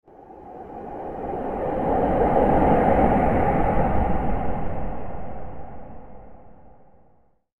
دانلود آهنگ باد 46 از افکت صوتی طبیعت و محیط
جلوه های صوتی
دانلود صدای باد 46 از ساعد نیوز با لینک مستقیم و کیفیت بالا